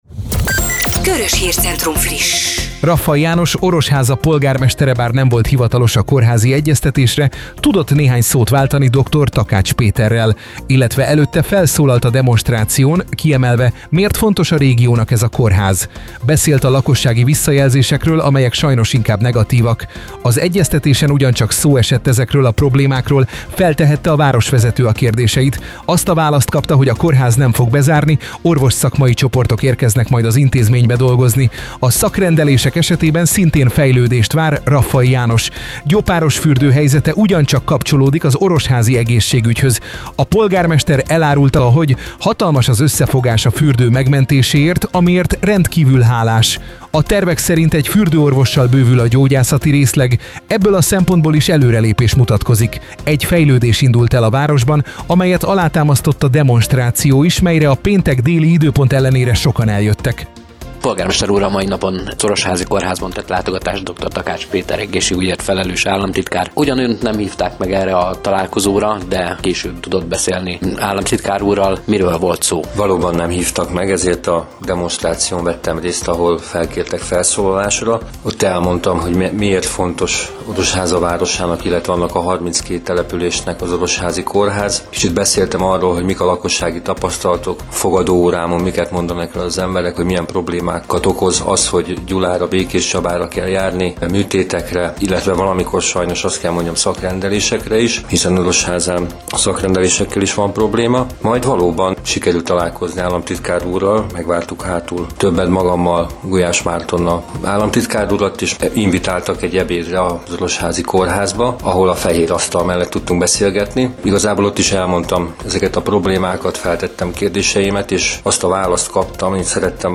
A nagy érdeklődésre való tekintettel az OrosFM-en hétfőn elhangzott rádiós műsor anyagát most közzétesszük, alább meghallgathatják.
A Körös Hírcentrum az OrosFM-en is beszámolt az eseményen készült hangfelvételekkel és interjúkkal.
A rádiós műsor tartalmazta Kátai Attila (Momentum), a Békés Vármegyei Önkormányzat képviselőjének beszédét, valamint a Sebők Évával (Momentum), Orosháza parlamenti képviselőjével és a Raffai Jánossal, Orosháza polgármesterével készült interjúkat, amelyeket most a honlapunkon is közlünk.